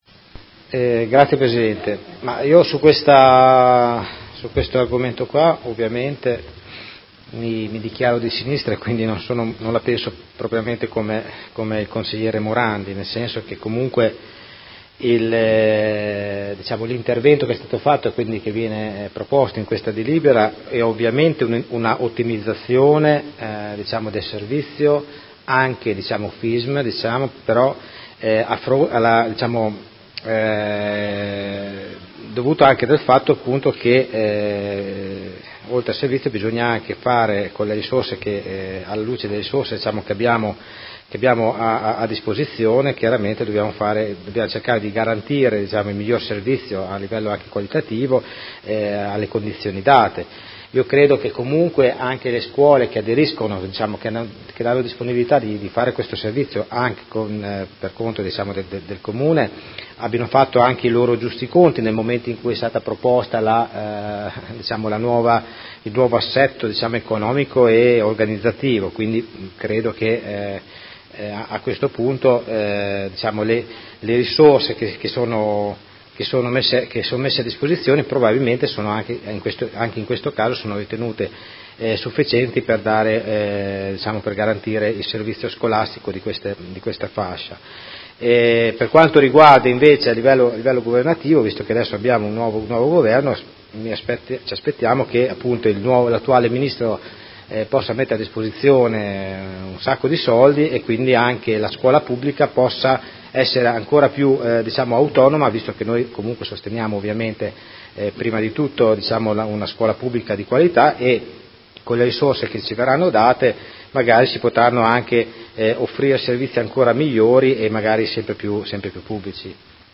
Vincenzo Walter Stella — Sito Audio Consiglio Comunale
Seduta del 25/10/2018 Dibattito.